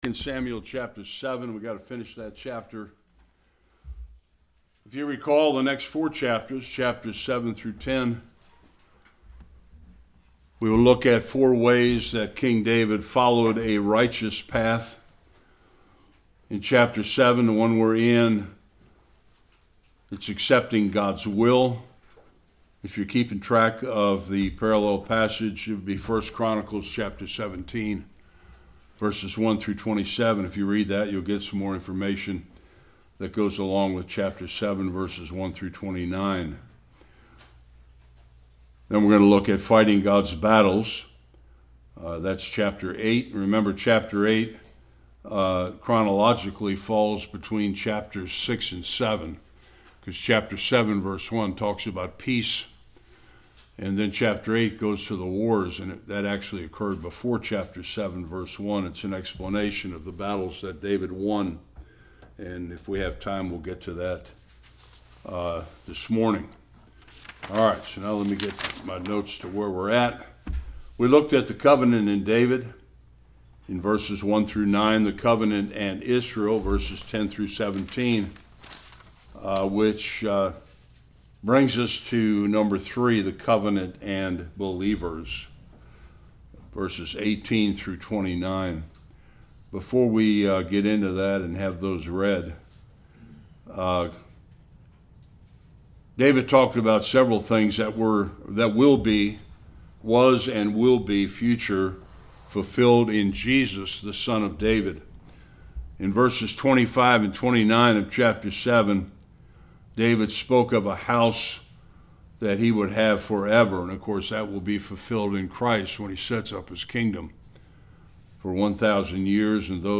1-2 Service Type: Sunday School David’s prayer and praise of thanksgiving for the LORD’s covenant with him.